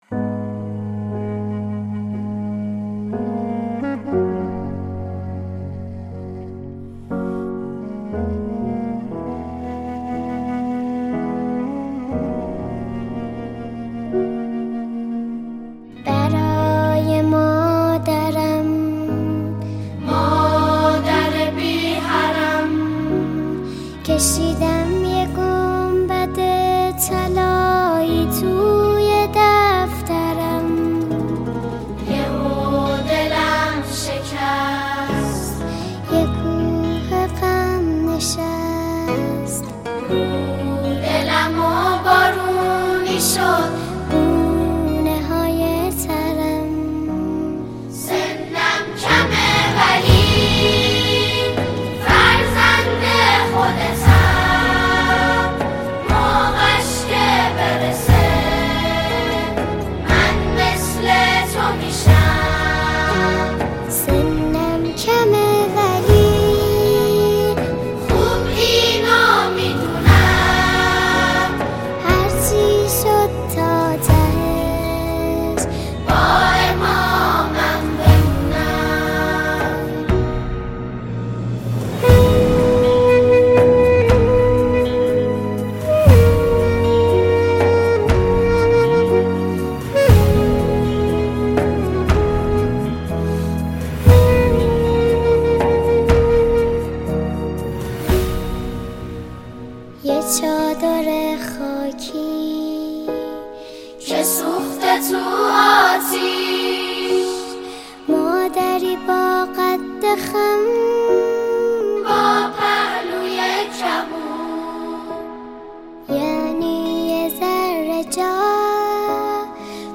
نماهنگ احساسی و تاثیرگذار
ژانر: سرود